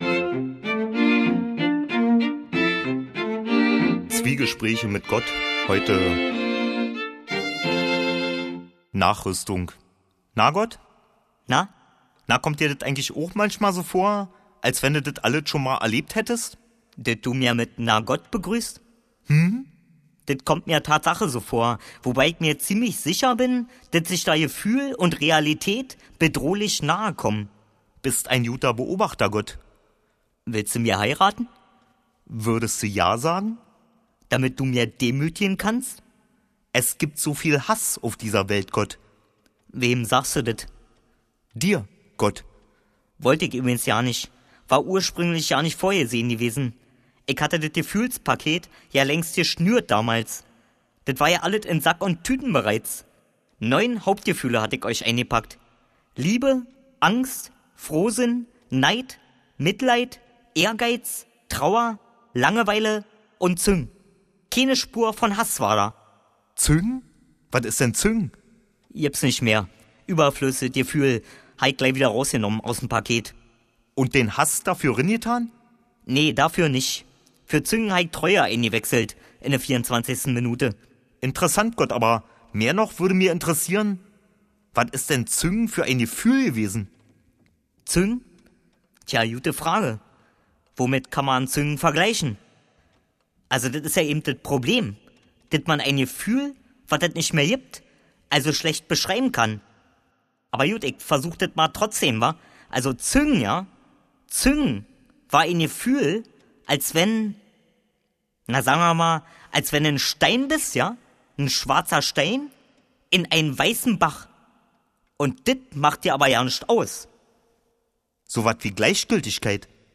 Eine Lesung